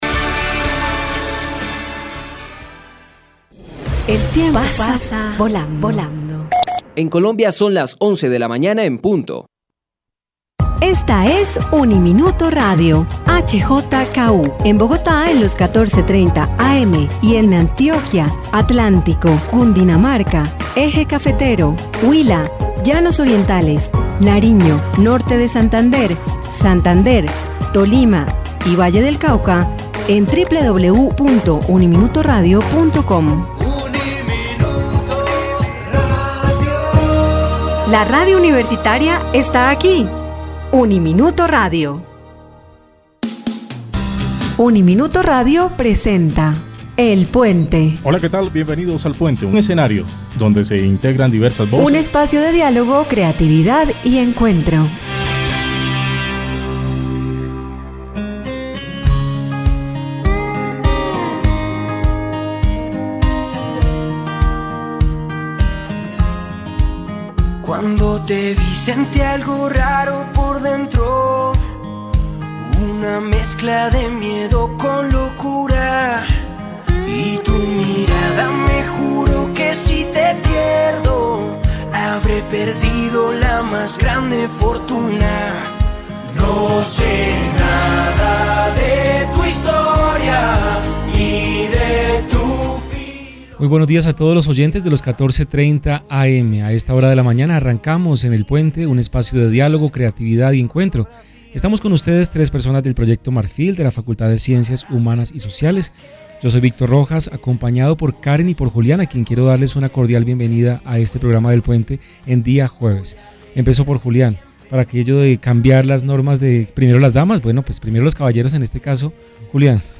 son reflexiones que compartimos en nuestro dialogo con nuestra invitada